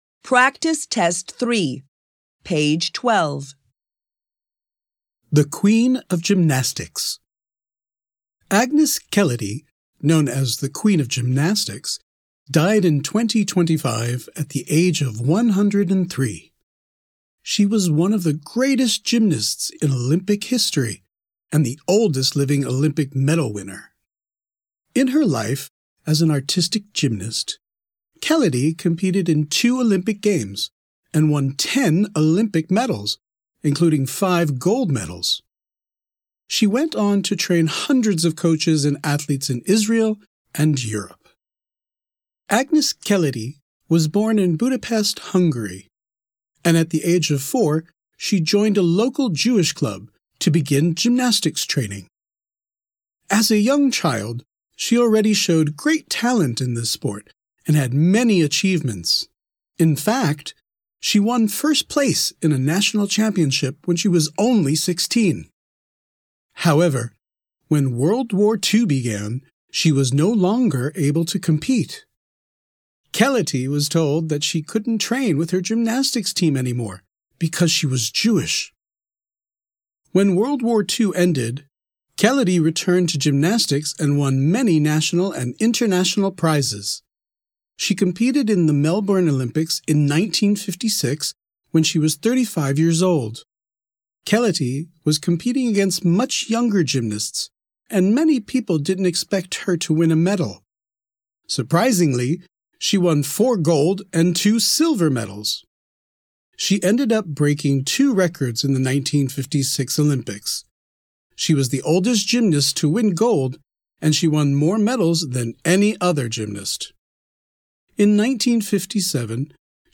ECB Online offers you audio recordings of the reading texts from your coursebook to help you understand and enjoy your lessons.